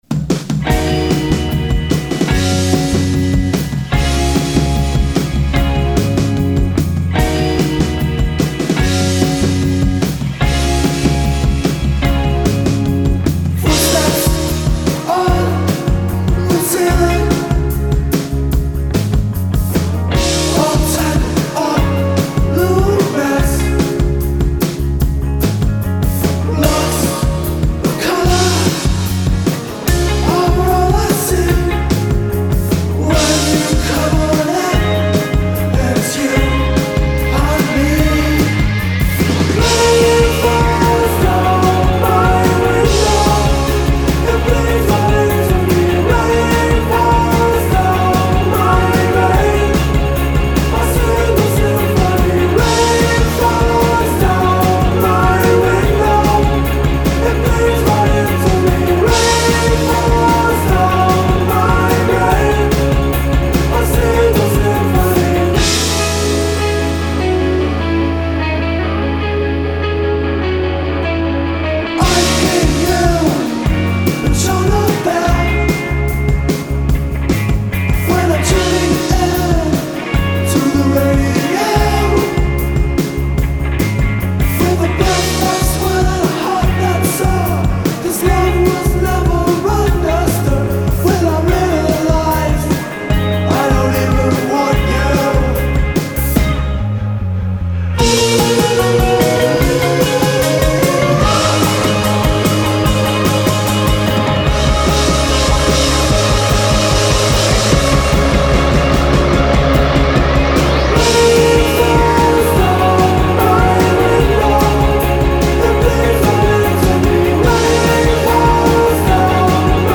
lush harmonies